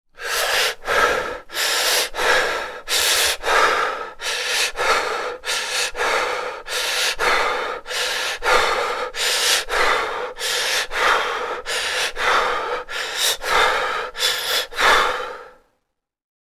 دانلود صدای تند تند نفس کشیدن از ساعد نیوز با لینک مستقیم و کیفیت بالا
جلوه های صوتی
برچسب: دانلود آهنگ های افکت صوتی انسان و موجودات زنده